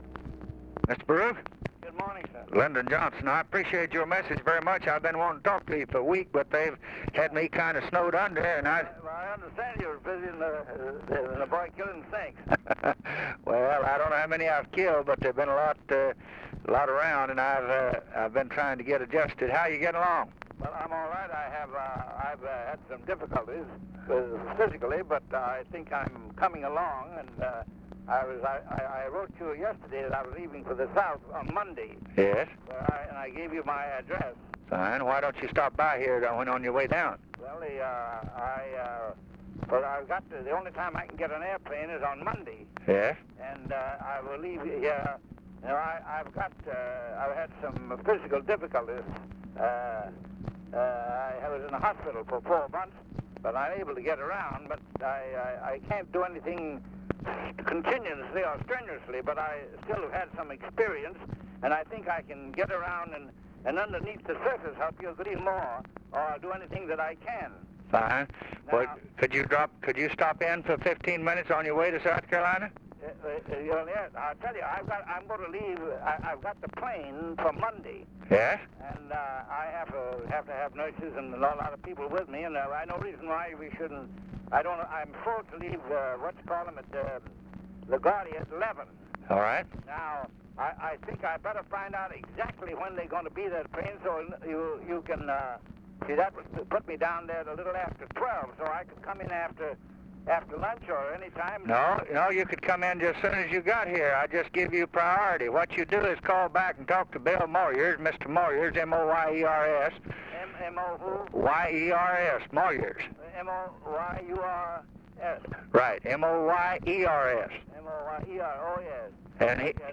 Conversation with BERNARD BARUCH, December 04, 1963
Secret White House Tapes